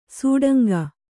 ♪ sūḍanga